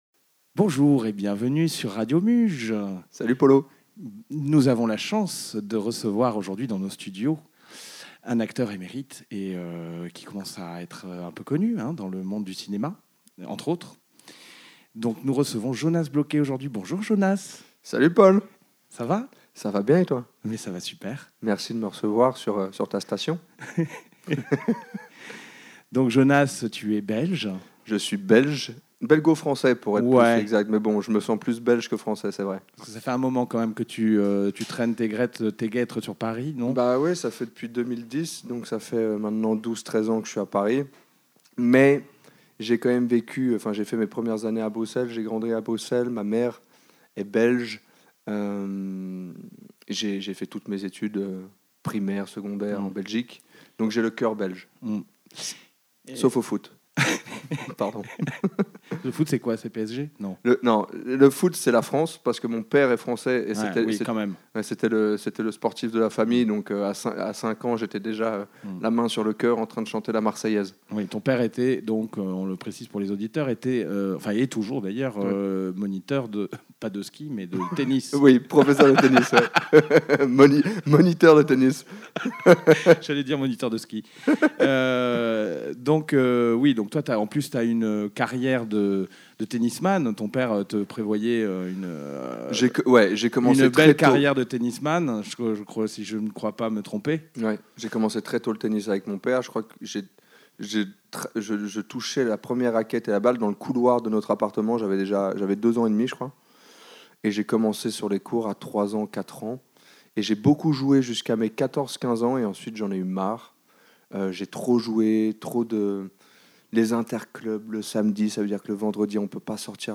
Jonas Bloquet se livre au micro des studios de Radio Muge pour une ITW à consommer sans modération et sans sulfites comme d'hab..